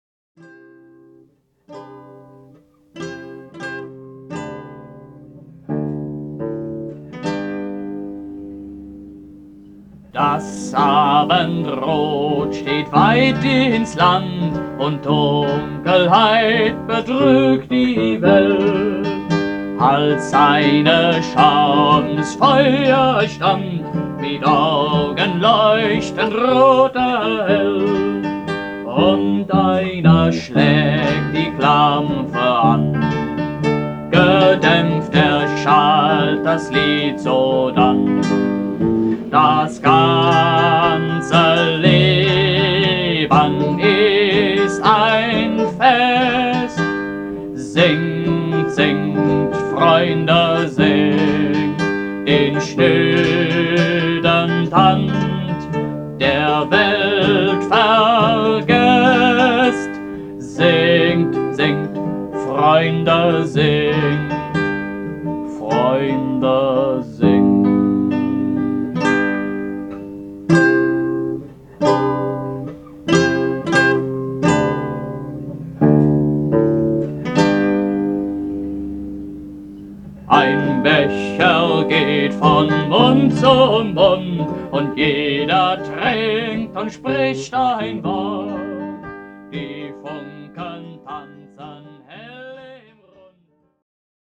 Aufnahmen aus dem Jahr 1976
Meine frühen Lieder